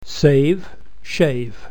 saveshave.mp3